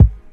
Kick 15.wav